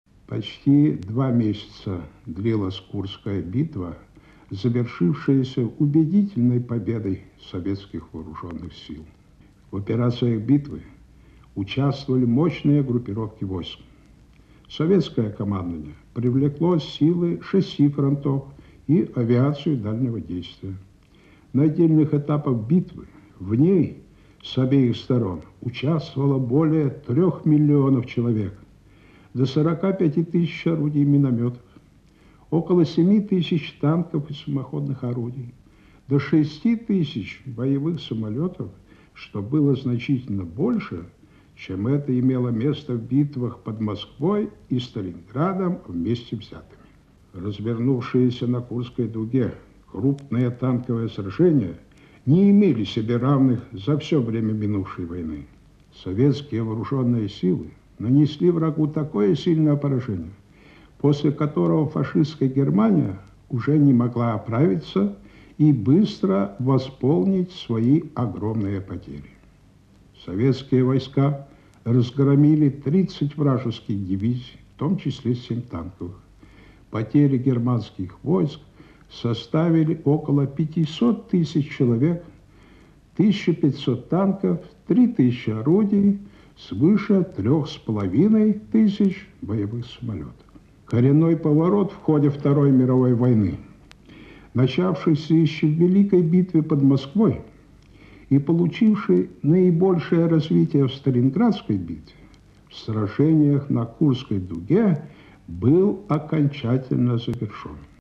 Маршал Советского Союза Александр Василевский делится воспоминаниями о Курской битве. (Архивная запись).